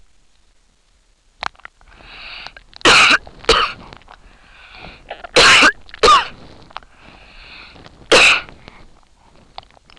coughing.wav